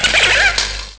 sound / direct_sound_samples / cries / galvantula.aif